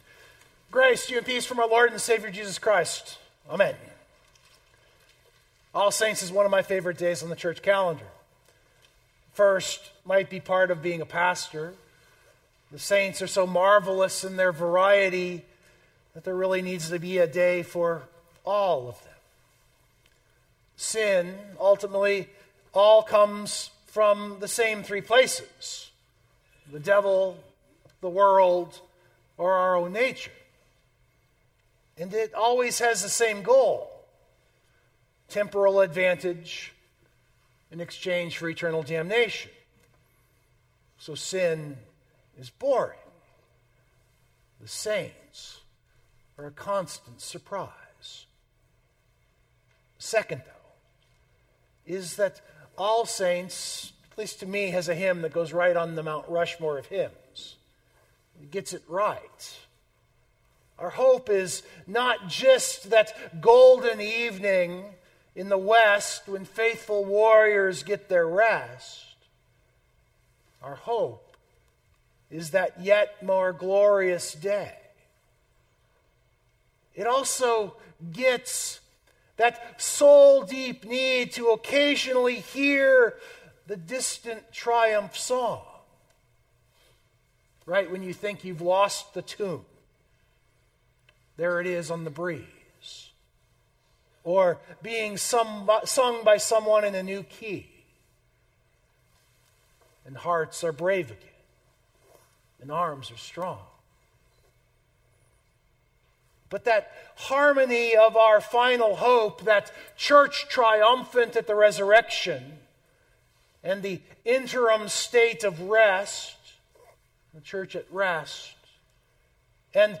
I hope that this sermon was an invitation to think beyond those simplistic reductions.